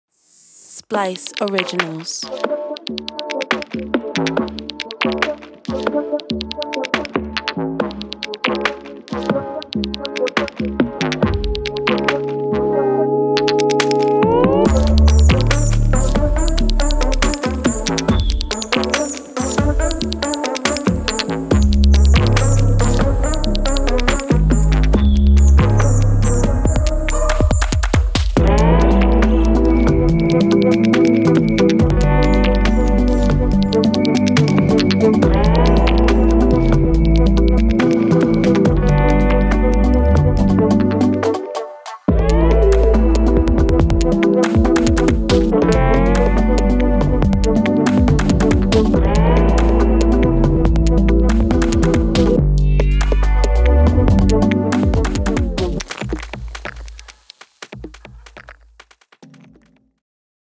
Experimental
percussive nature sounds made with sticks and stones